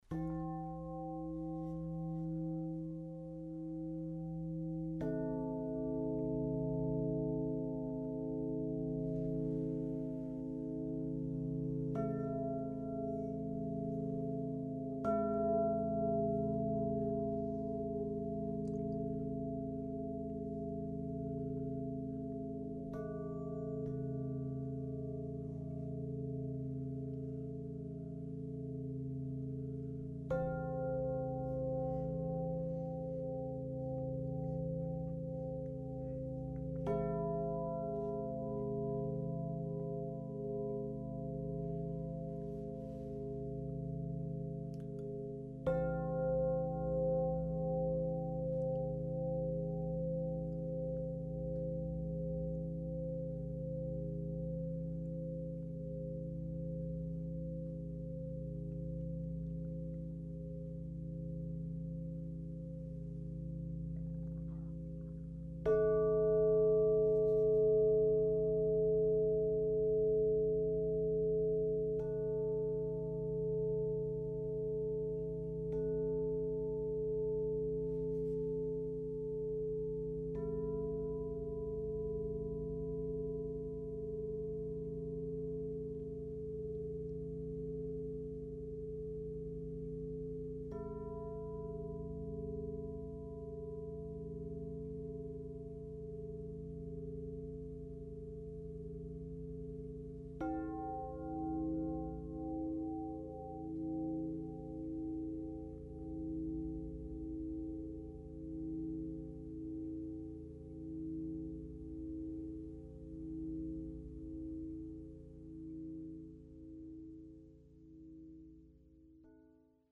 Wu wei – Klangrohre, Röhrengongs
Sie haben eine Länge bis 220 cm, haben ein weichen langanhaltenden Raumklang und können relativ genau gestimmt werden.
Sie haben einen warmen, tragenden und lang anhaltenden Klang und eignen sich für Meditation Therapie Yoga experimentelle Musik
Unten eine Klangprobe aus meiner Installation „Klangpavillion “ in Österreich